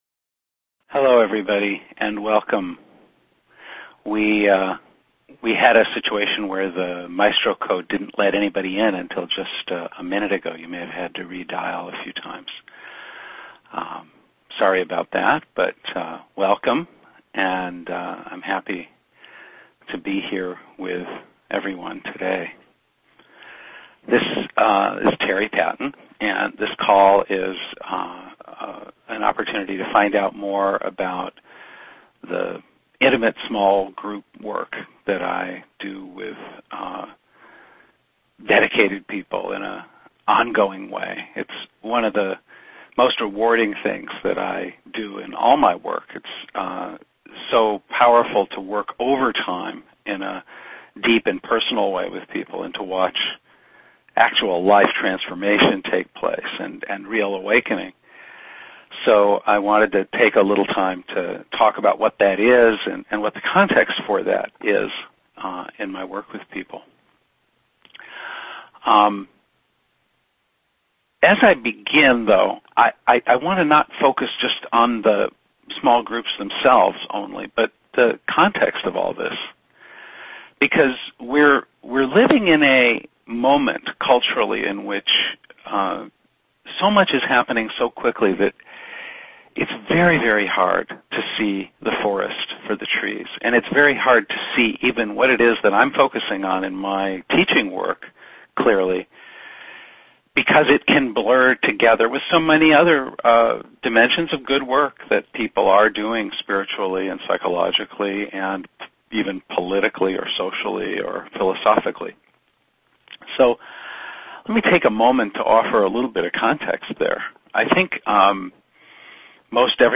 This call took place on Sunday, June 23, from 9:30-11 AM PDT.